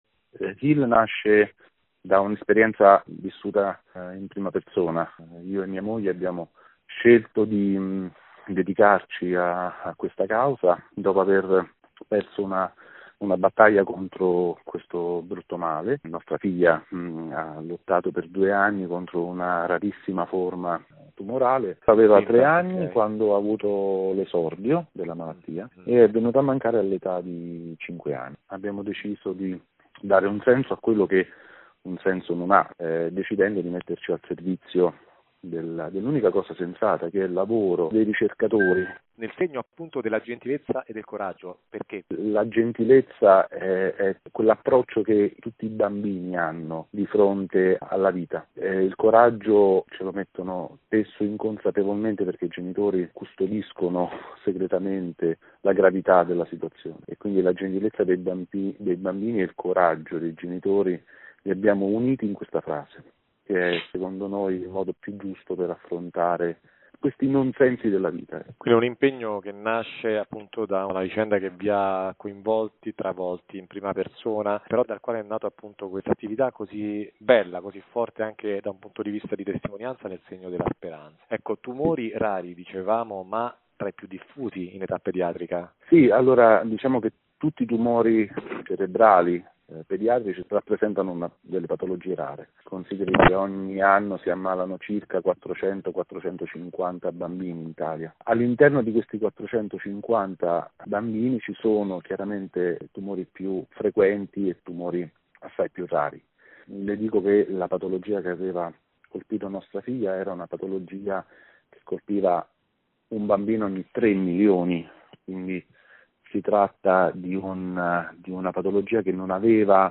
Intervista-Vatican-News.mp3